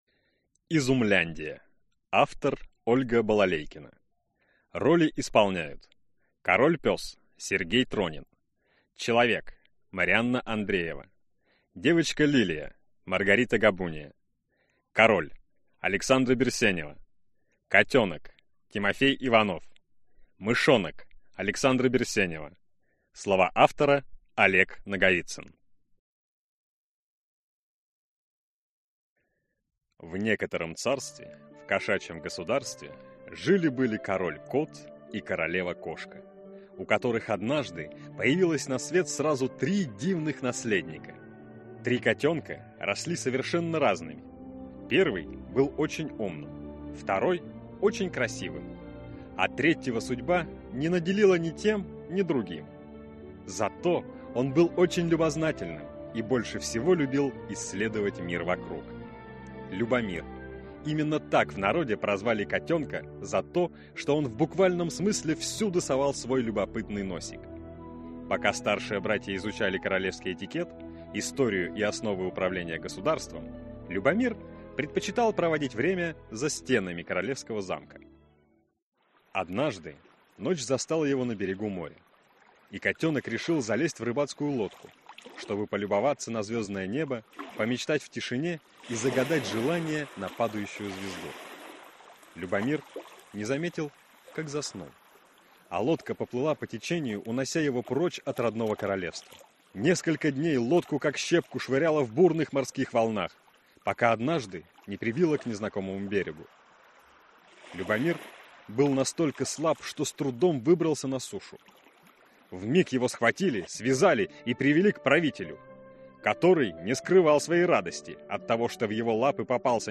Аудиокнига Изумляндия | Библиотека аудиокниг